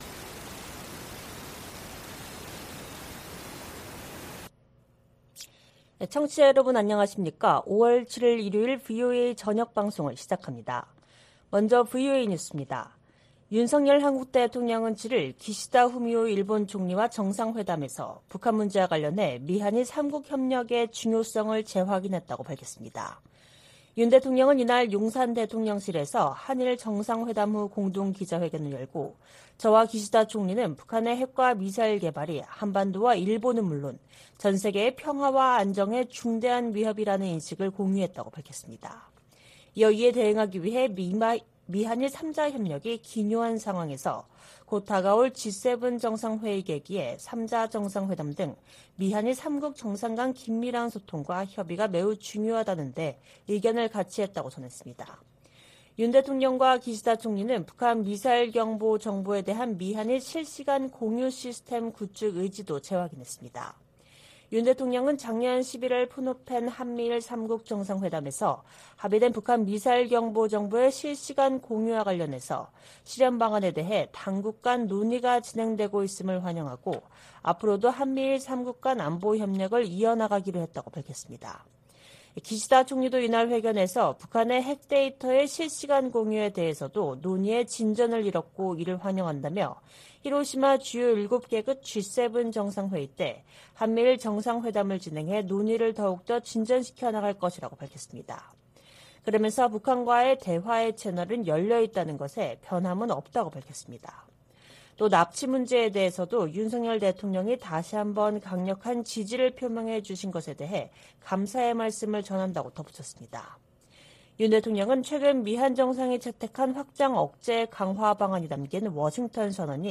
VOA 한국어 방송의 일요일 오후 프로그램 1부입니다.